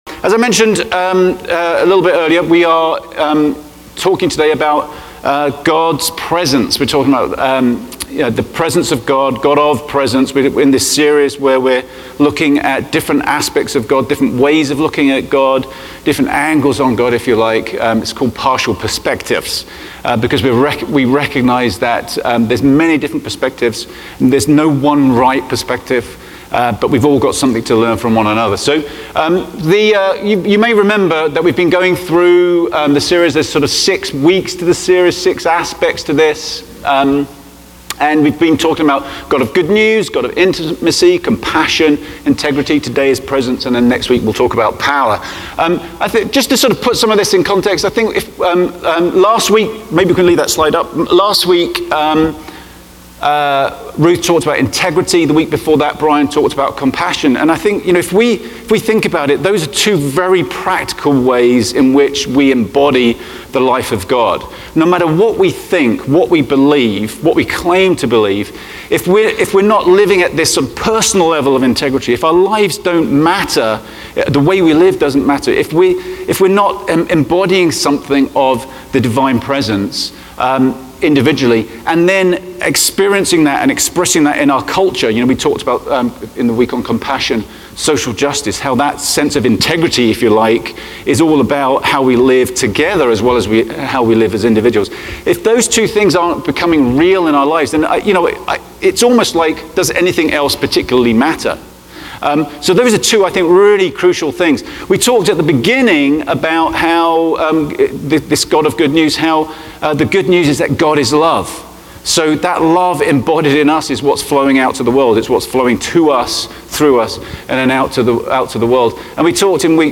A message from the series "It's Not About Me." During today's service for all ages, we explore how we're all connected to one another and to God.